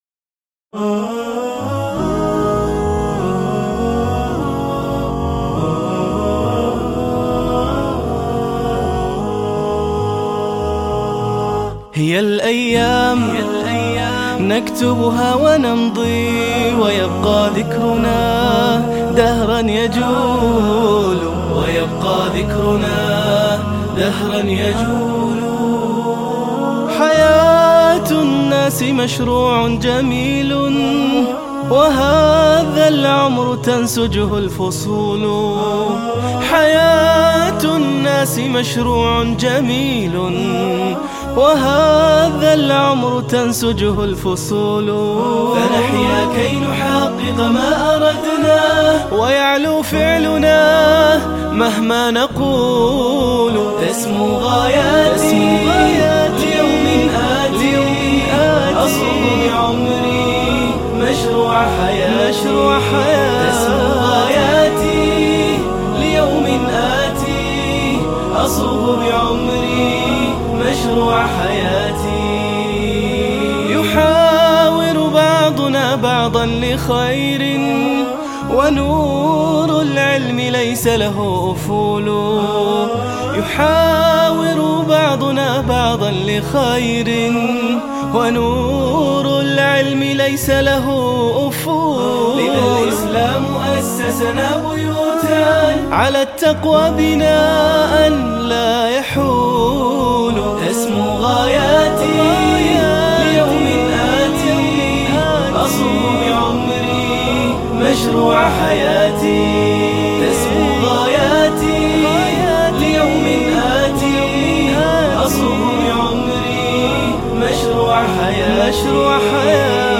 نشيدة
للتحميل بدون إيقاع "آهات"